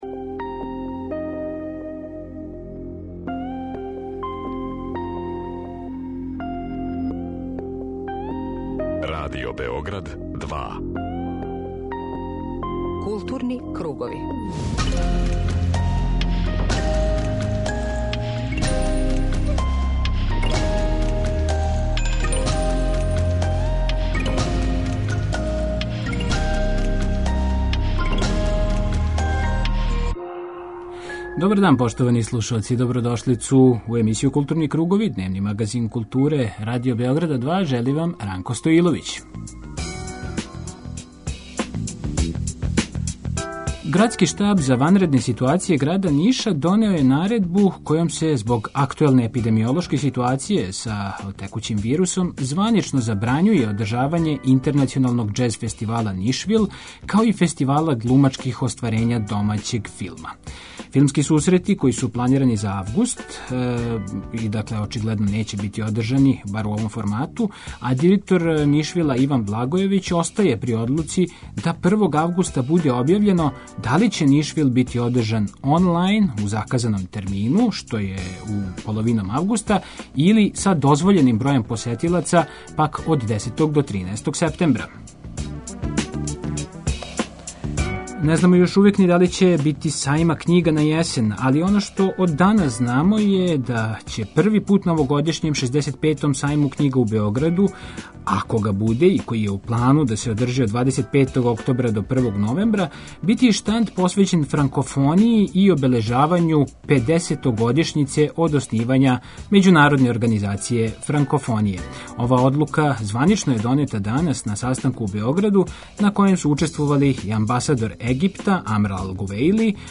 преузми : 19.57 MB Културни кругови Autor: Група аутора Централна културно-уметничка емисија Радио Београда 2.